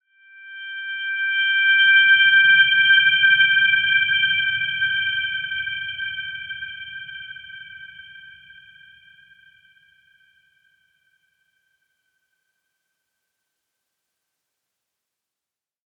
Dreamy-Fifths-G6-mf.wav